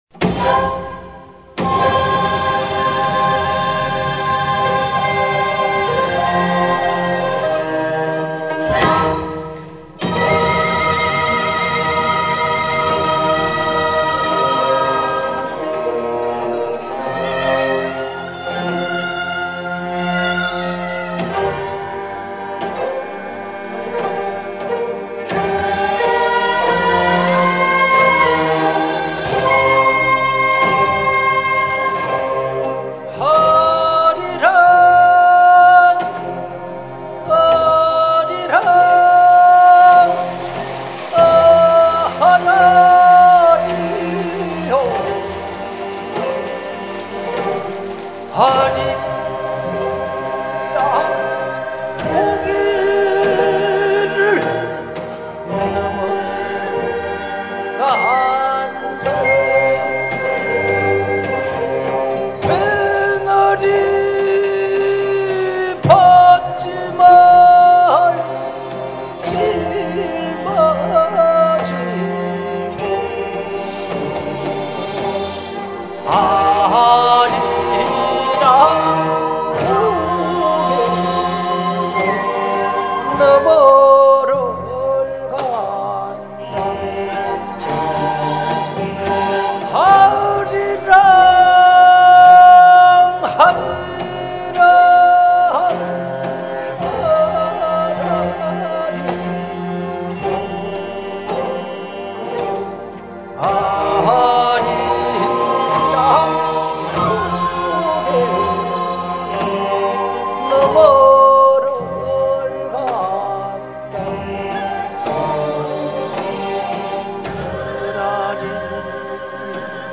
을숙도문화회관 대극장